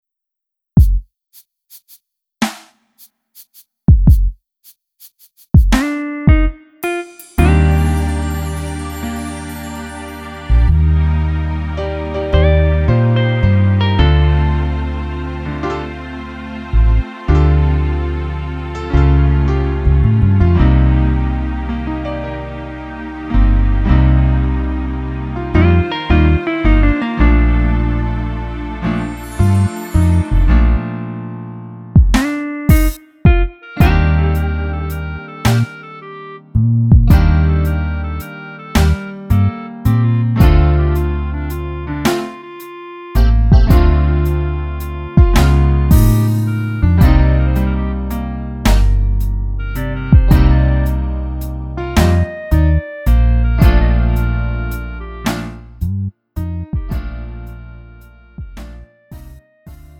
음정 -1키 3:41
장르 가요 구분